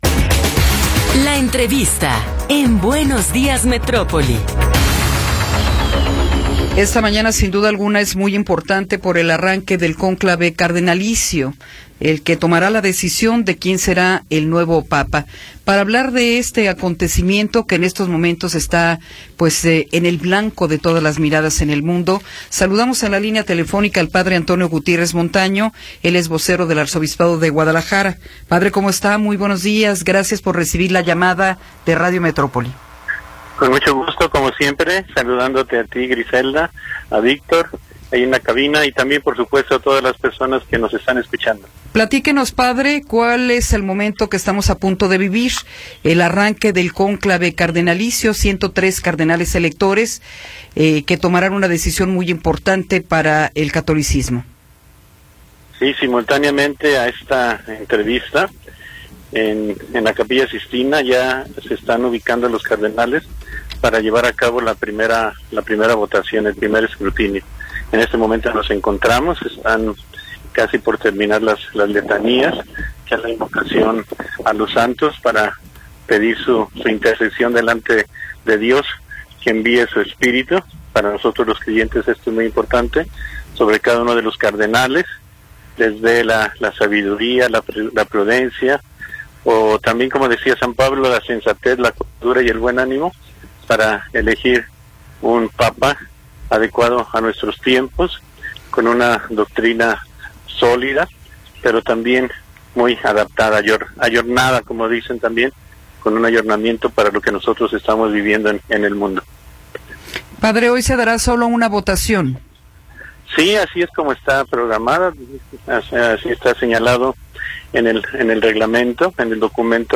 Entrevista
Entrevistas